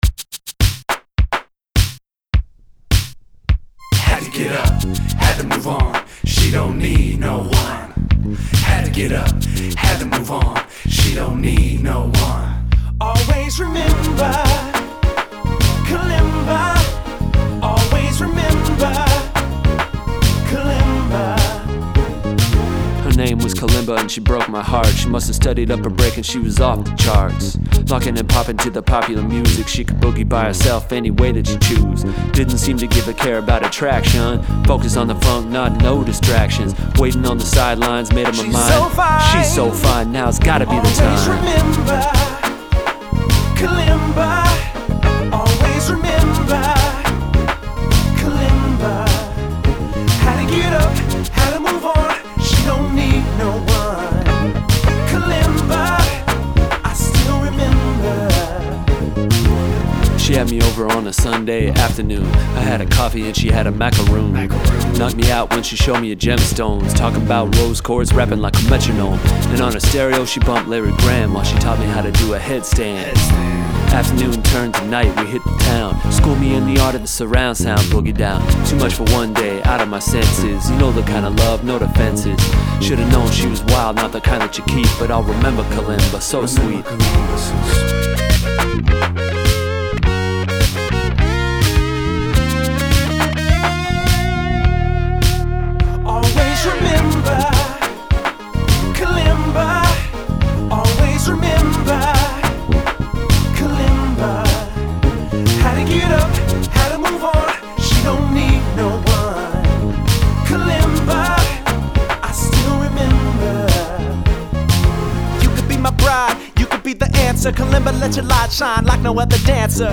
full of glitches, beats, rhymes, and serious jokes.
slappa d bass